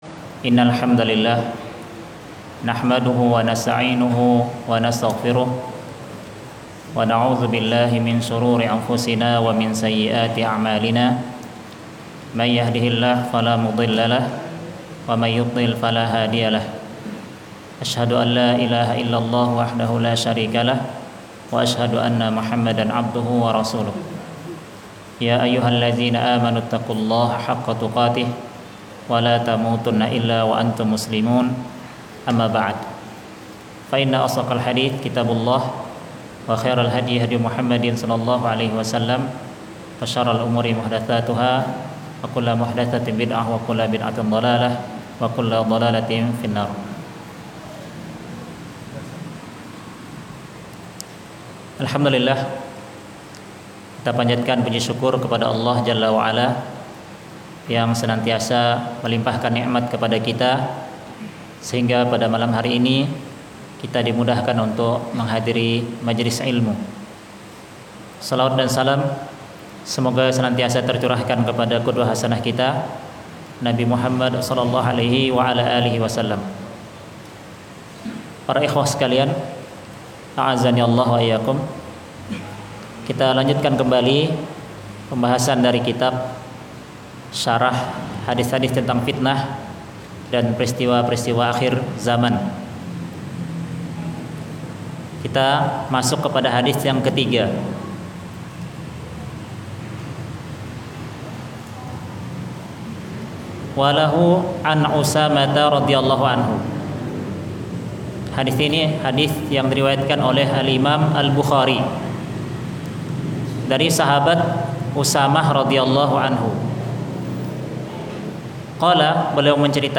kajian